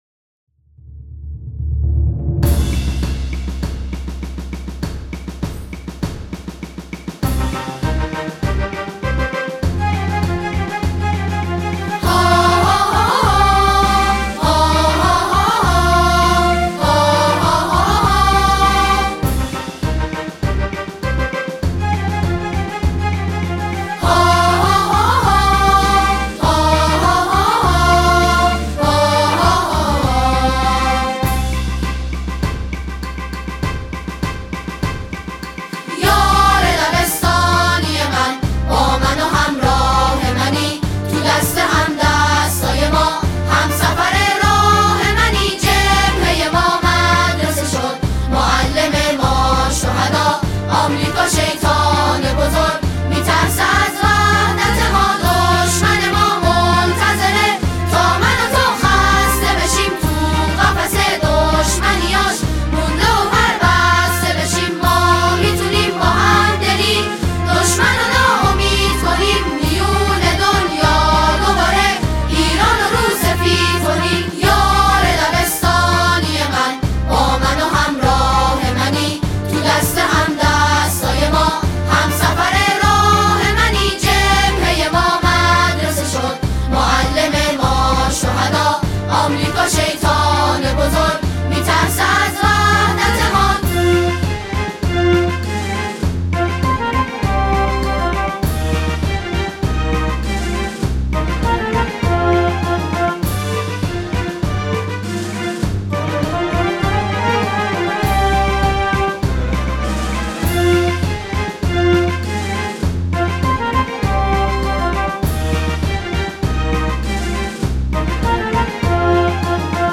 آهنگ با صدای زن